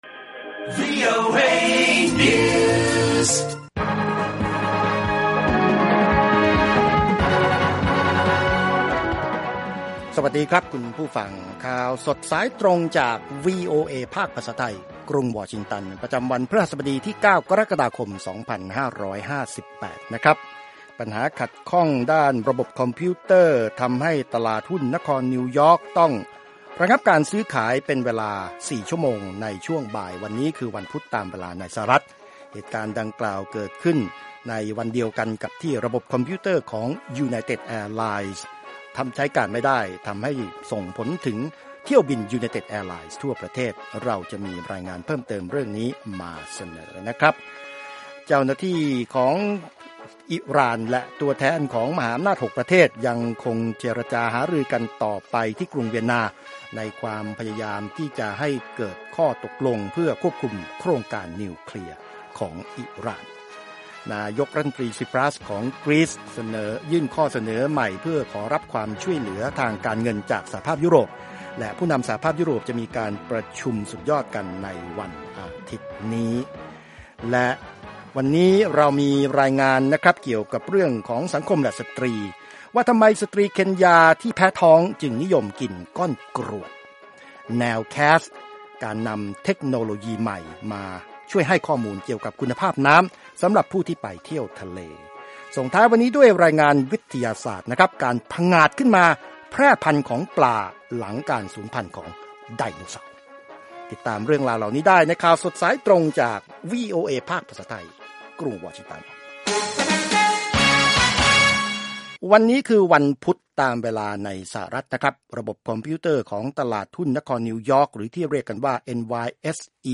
ข่าวสดสายตรงจากวีโอเอ ภาคภาษาไทย 6:30 – 7:00 น. พฤหัสบดีที่ 15 กรกฎาคม 2558